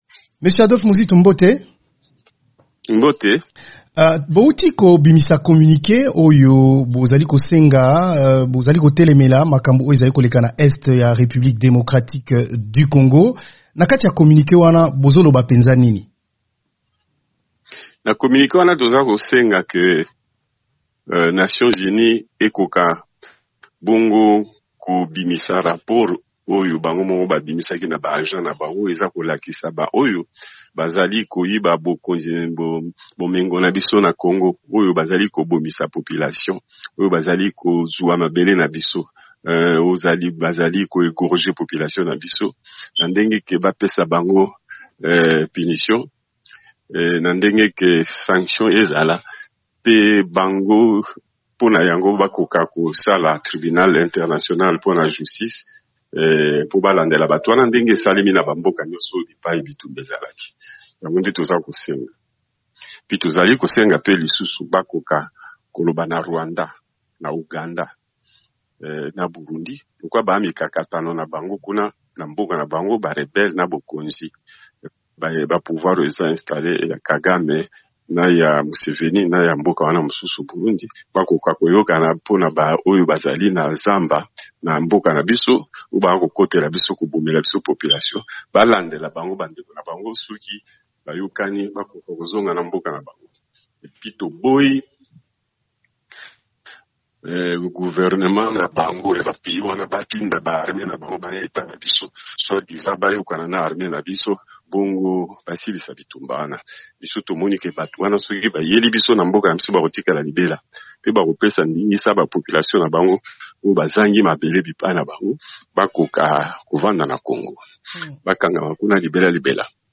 atunaki mokambi ya sika ya ngambo moko ya Lamuka,, Adolphe Muzito.